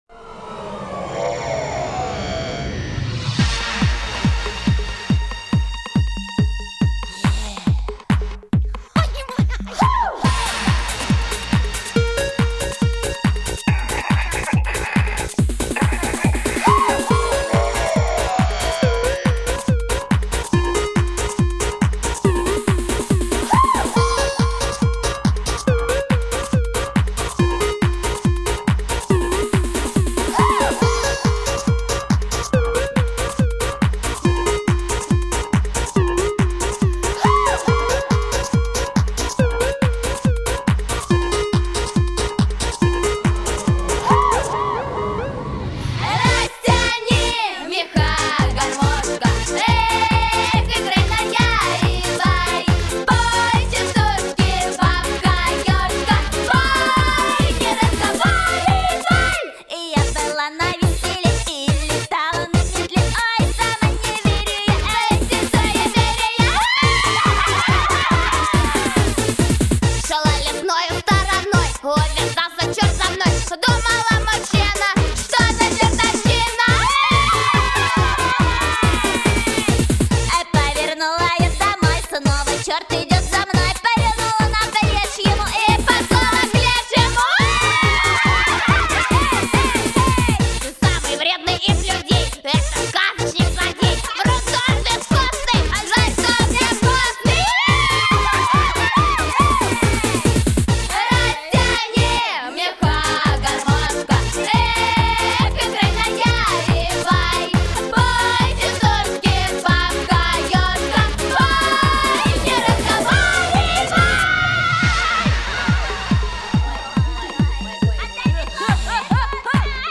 • Категория: Частушки